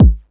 cch_kick_low_punch_curve.wav